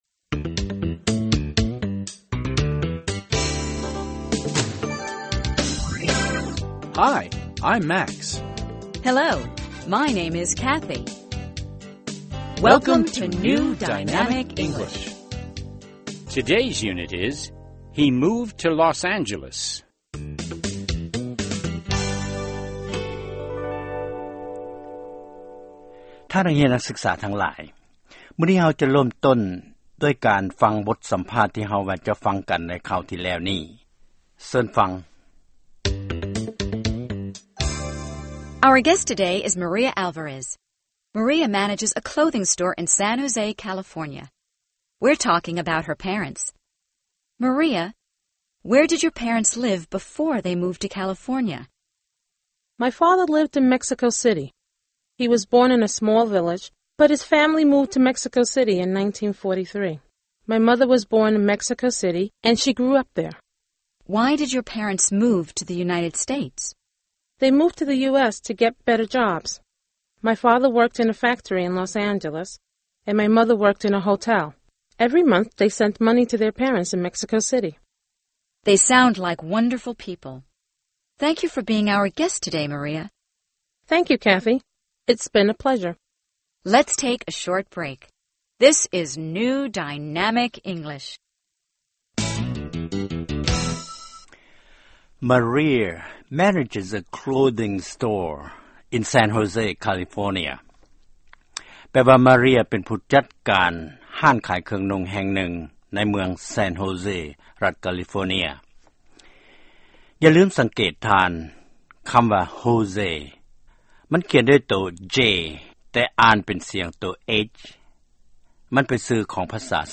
ຟັງບົດຮຽນພາສາອັງກິດ